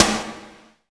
12 SNARE 3-L.wav